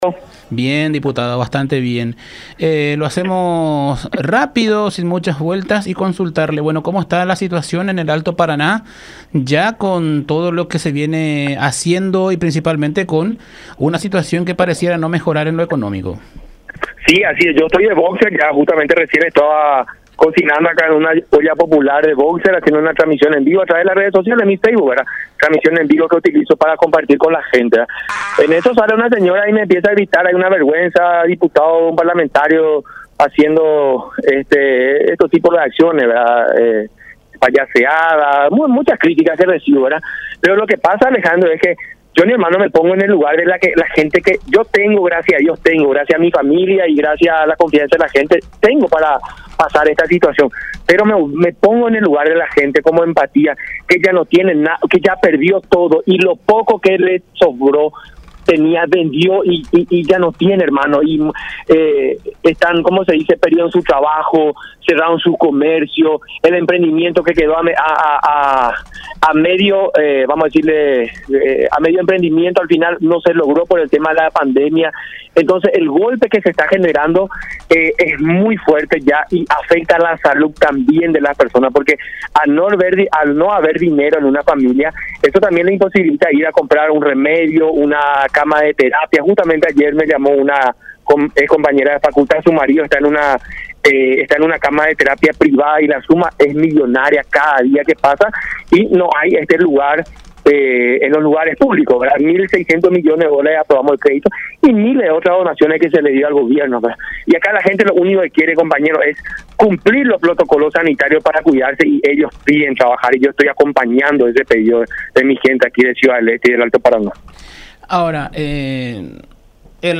Britez en dialogo con La Unión R800 AM sostuvo que  hay una violación de las garantías constitucionales, y que él seguirá denunciando está situación, esperando la apertura de las fronteras.